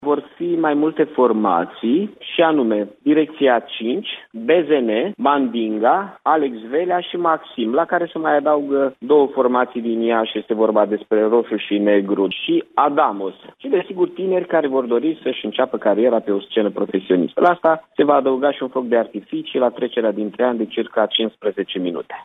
Primarul interimar Mihai Chirica a declarat pentru postul nostru de radio că ieșenii se vor putea bucura de Revelion și de un spectaculos foc de artificii, care va dura 15 minute.